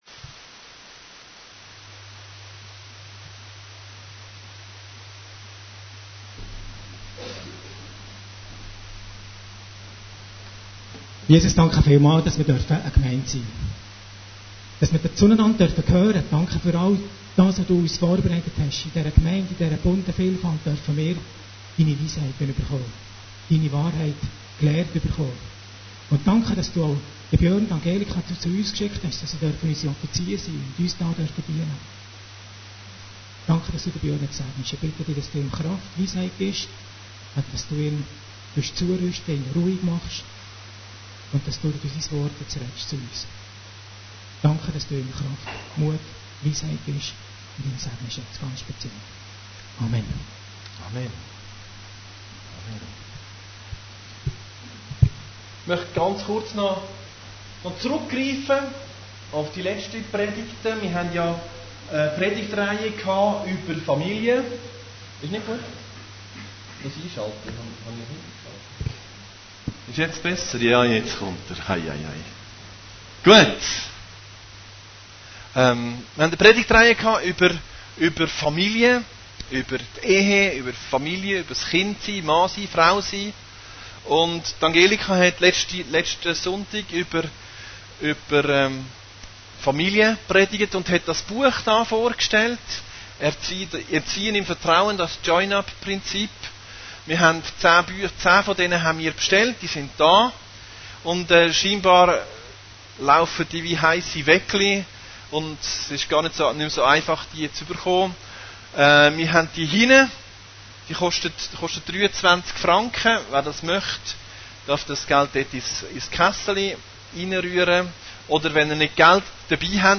Predigten Heilsarmee Aargau Süd – Gottesdienst und Gemeinschaft